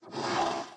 骷髅马：嘶叫
骷髅马马在在陆地上空闲时
Minecraft_skeleton_horse_idle2.mp3